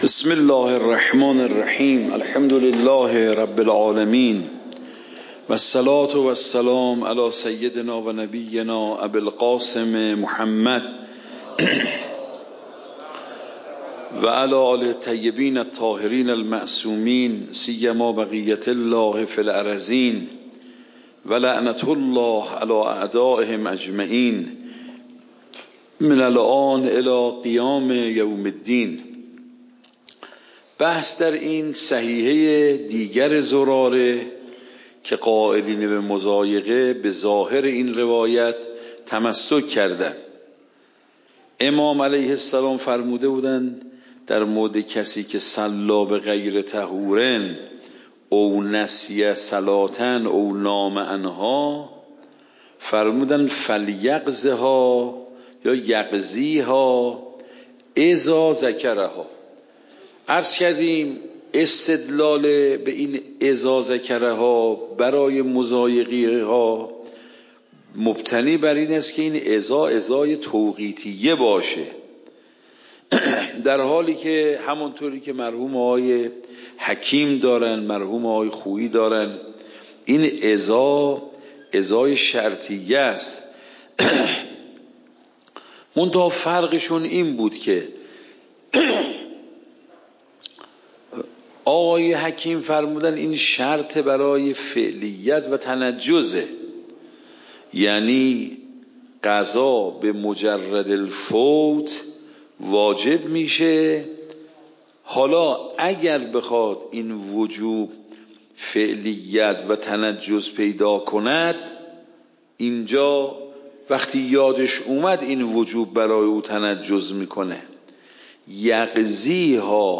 درس بعد مواسعه و مضایقه درس قبل مواسعه و مضایقه درس بعد درس قبل موضوع: صلاة قضاء (مواسعه و مضایقه) فقه خارج فقه مباحث صلاة القضاء صلاة قضاء (مواسعه و مضایقه) تاریخ جلسه : ۱۴۰۴/۲/۶ شماره جلسه : ۸۲ PDF درس صوت درس ۰ ۳۴۳